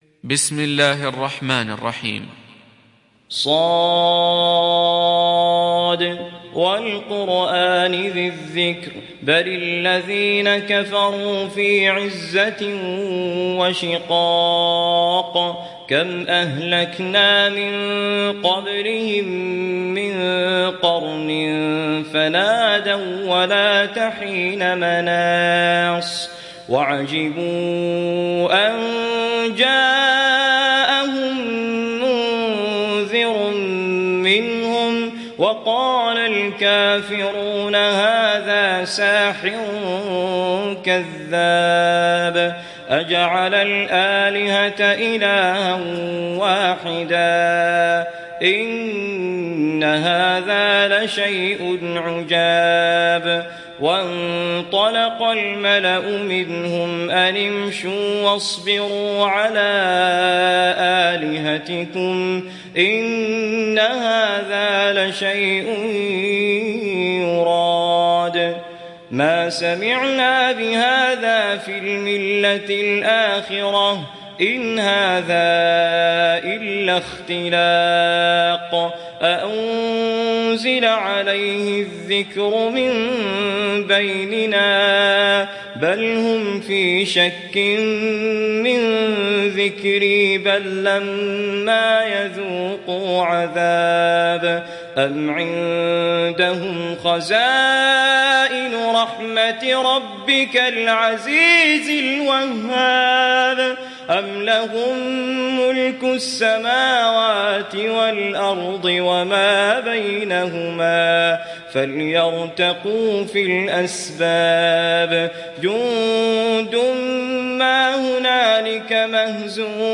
برواية حفص عن عاصم